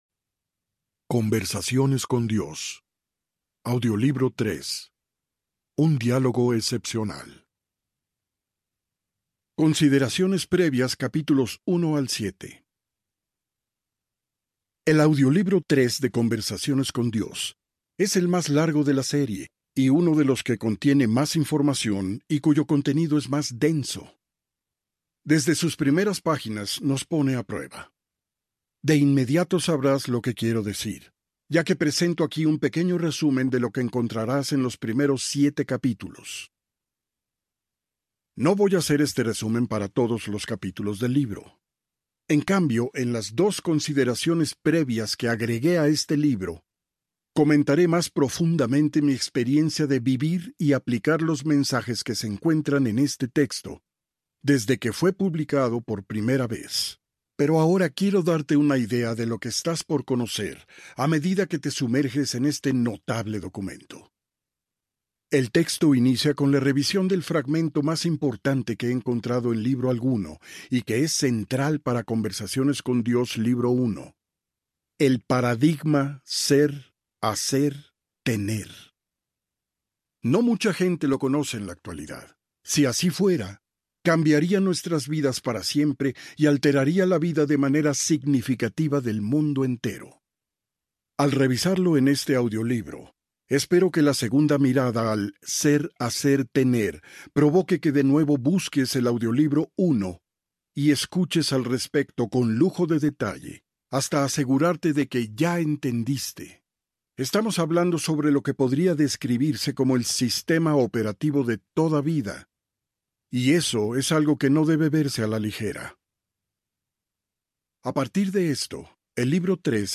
Conversaciones con Dios III (Conversaciones con Dios 3) Audiobook
Narrator
14.1 Hrs. – Unabridged